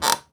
chair_frame_metal_creak_squeak_09.wav